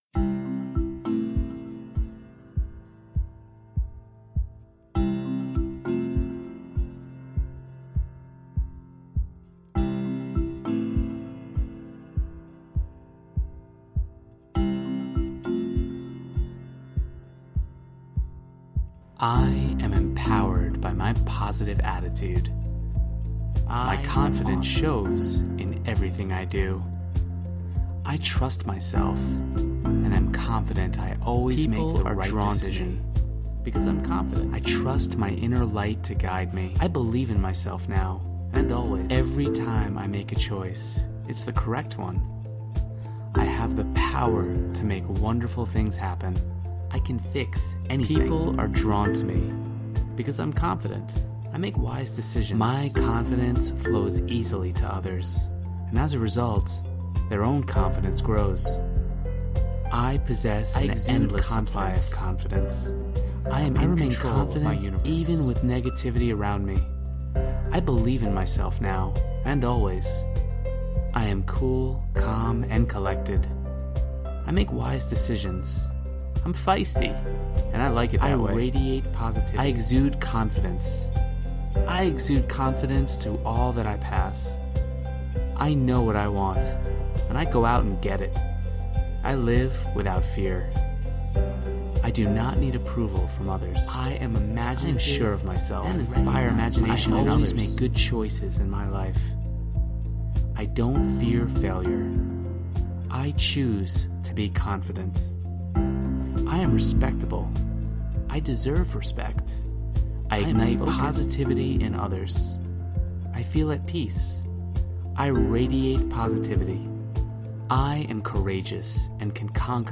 Positive Affirmations
confidentaffirmations.mp3